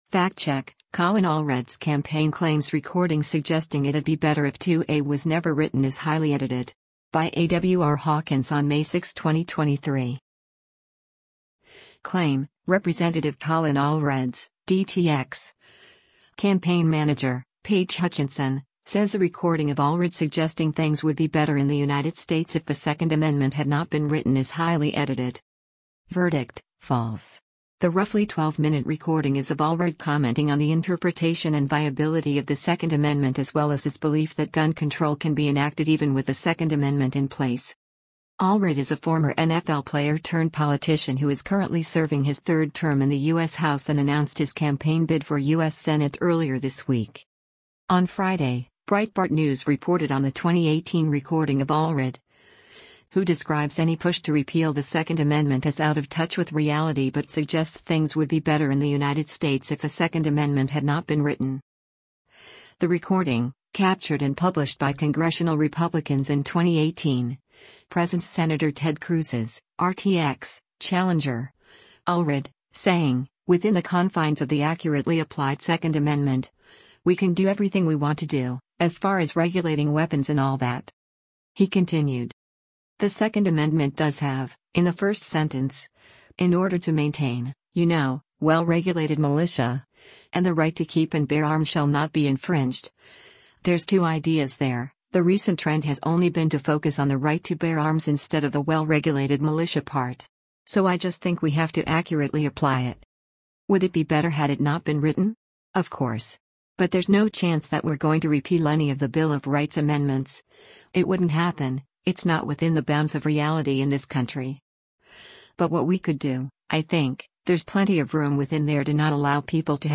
The clip embedded above is Allred in his own words.